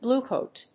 アクセント・音節blúe・còat